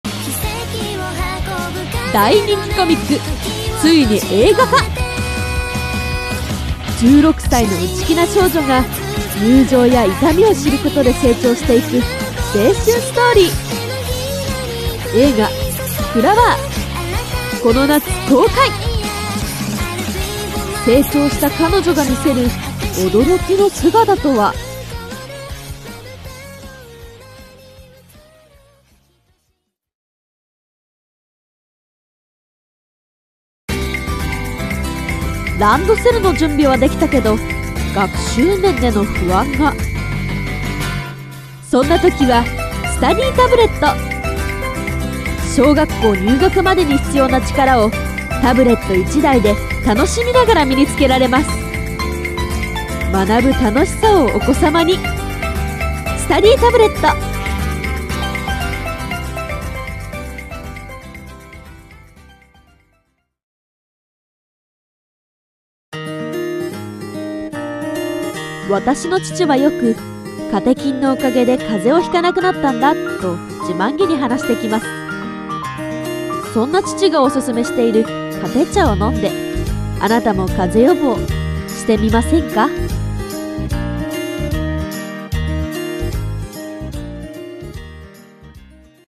ラジオCM風【台本】一人声劇